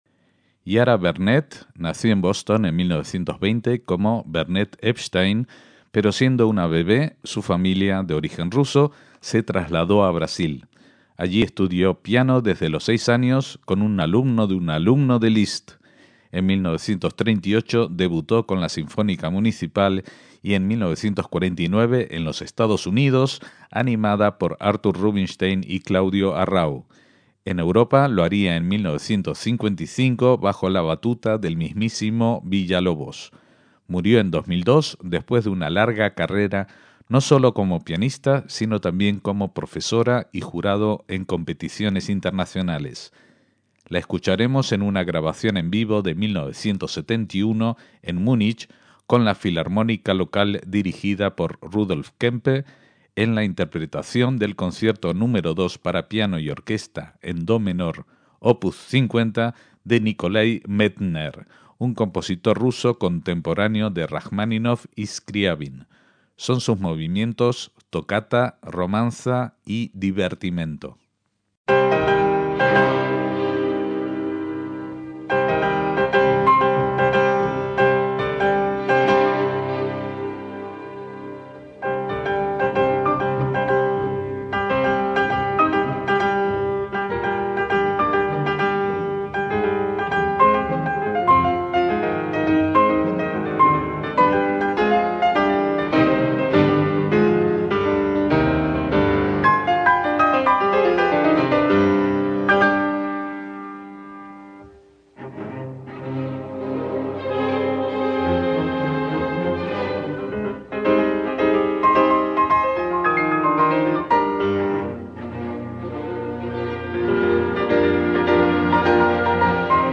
MÚSICA CLÁSICA
pianista